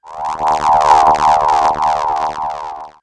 ihob/Assets/Extensions/RetroGamesSoundFX/Hum/Hum18.wav at master
Hum18.wav